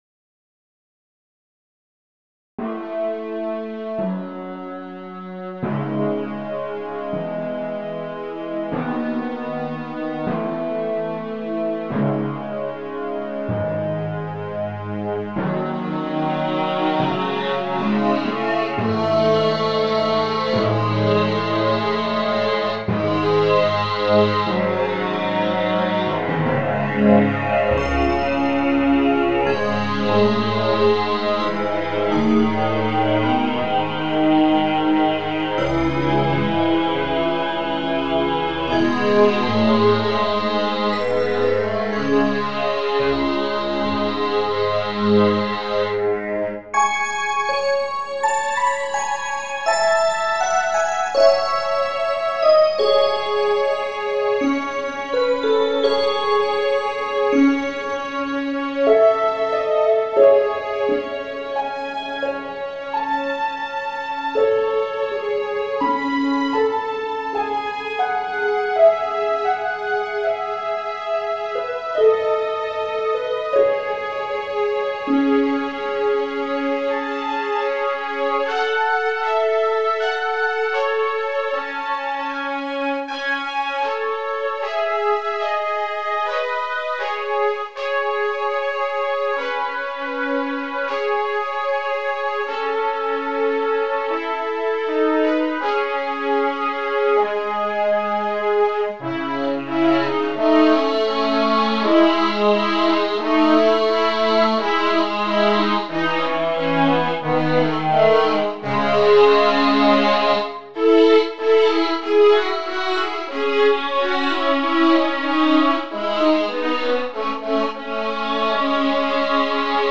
Posted in Classical Comments Off on